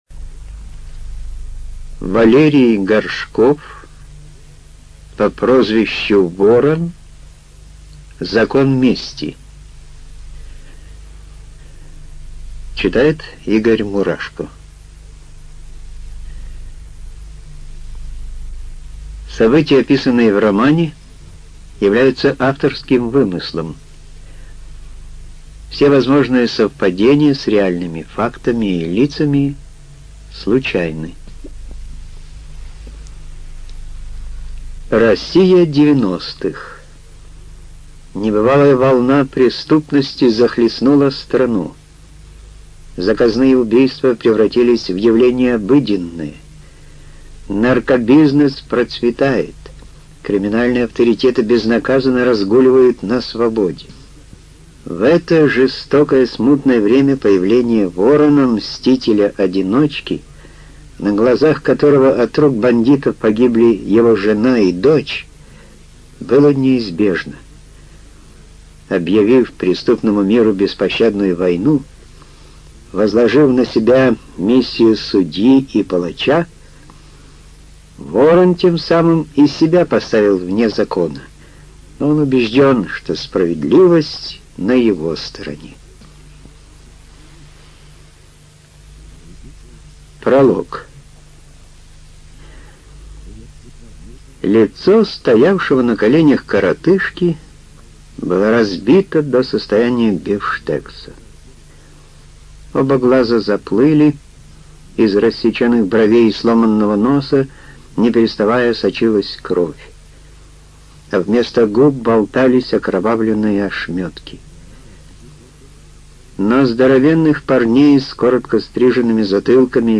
ЖанрБоевики